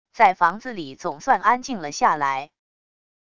在房子里总算安静了下来wav音频生成系统WAV Audio Player